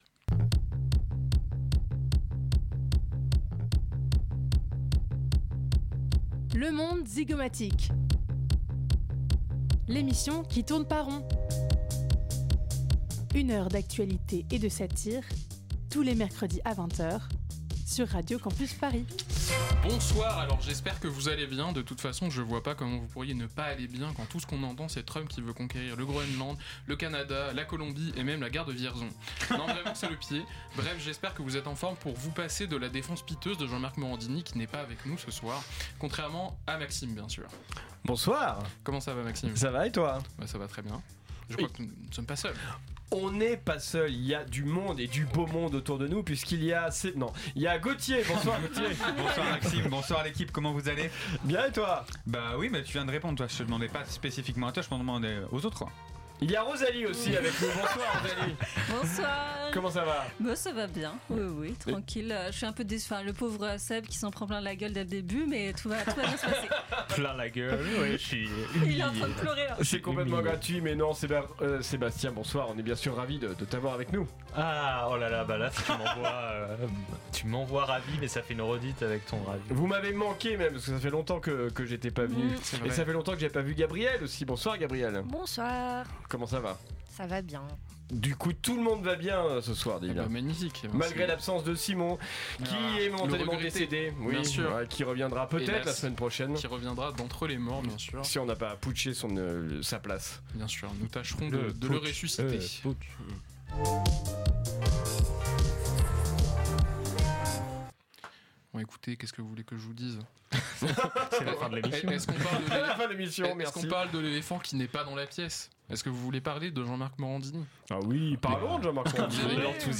Magazine Société